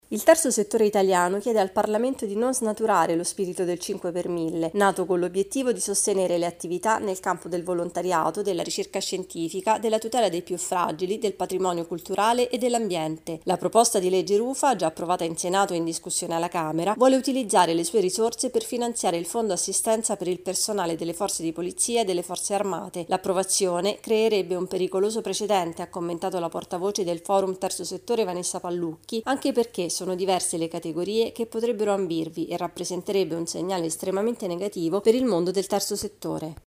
Il Forum del Terzo Settore è contrario all’utilizzo delle risorse del 5 per mille per altri scopi. Il servizio